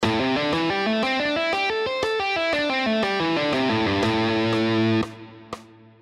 Here are the five positions of the A minor pentatonic scale, all played in triplets:
Triplet Lesson 1 – A Minor Pentatonic Position 1: